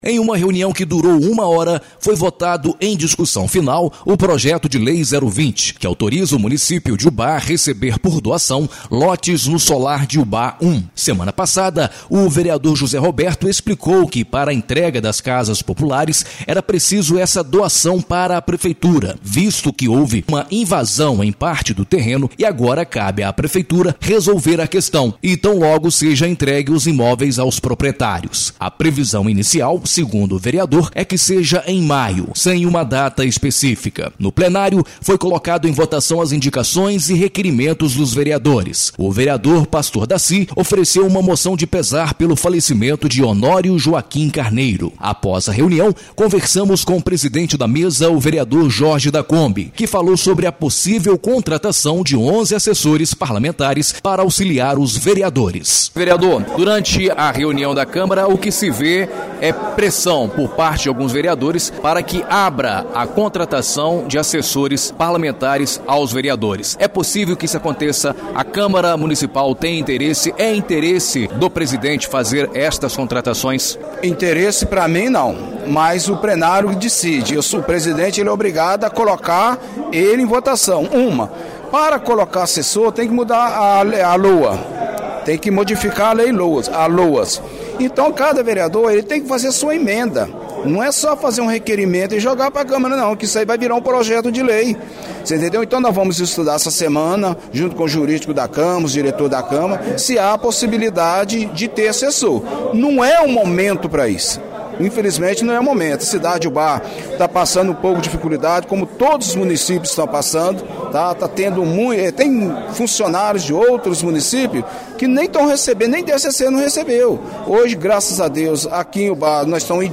Após a reunião conversamos com o presidente da mesa o vereador Jorge Gervásio – que falou sobre a possível contratação de 11 assessores parlamentares para auxiliar os vereadores.
INFORMATIVO EXIBIDO NA RÁDIO EDUCADORA AM/FM